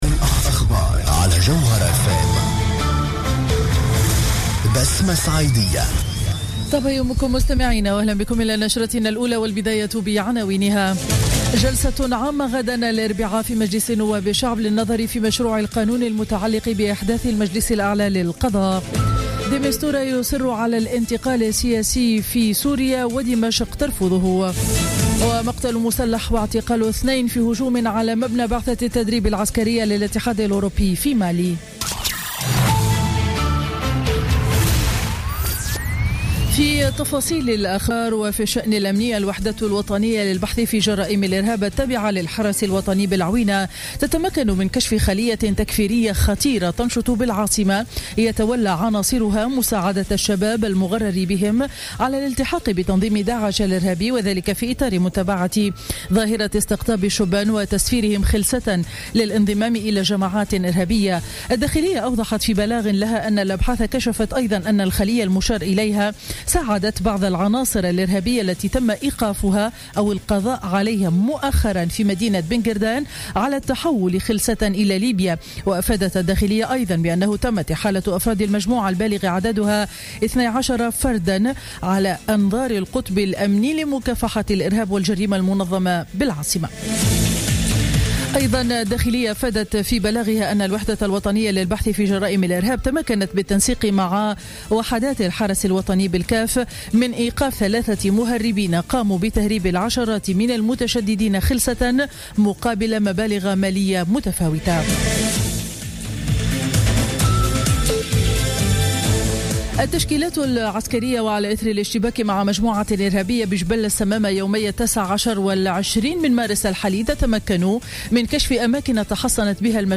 نشرة أخبار السابعة صباحا ليوم الثلاثاء 22 مارس 2016